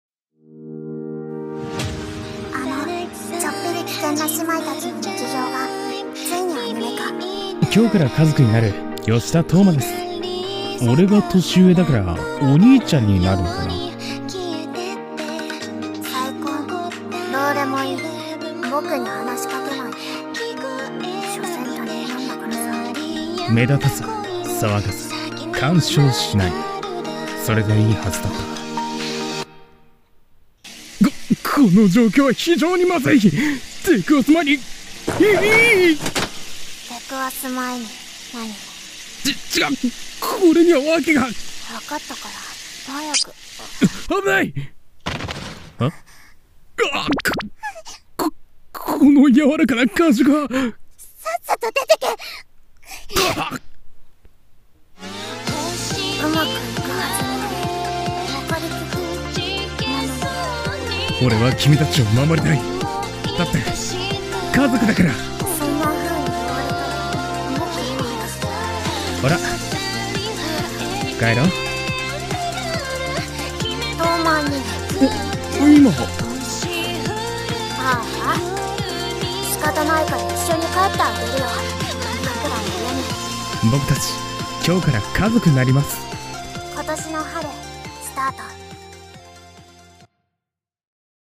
アニメ予告風声劇【僕達、今日から兄妹になります！】